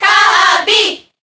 File:Kirby Cheer Japanese SSBB.ogg
File usage The following 2 pages use this file: Kirby (SSBB) List of crowd cheers (SSBB)/Japanese Transcode status Update transcode status No transcoding required.